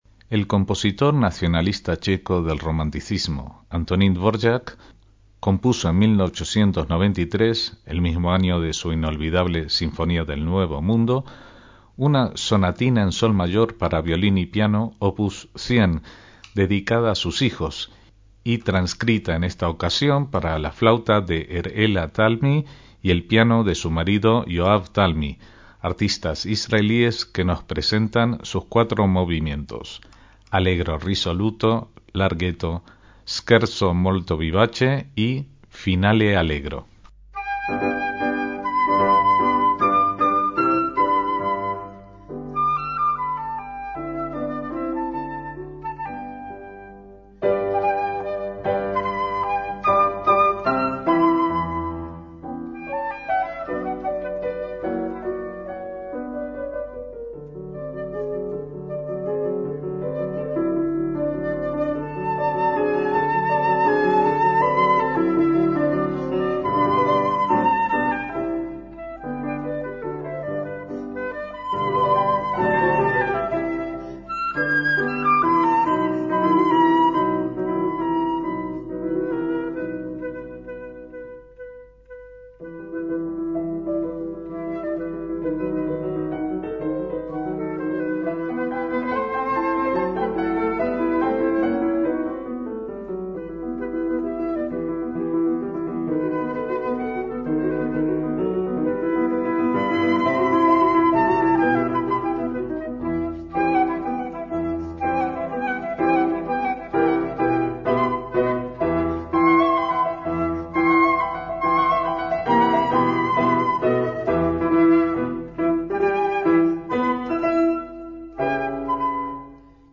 MÚSICA CLÁSICA
Originalmente, la pieza incluía violín y piano (dedicada a sus hijos), pero aquí puede escucharse con la flauta en sustitución del violín.